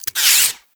Sfx_tool_spypenguin_retract_cam_01.ogg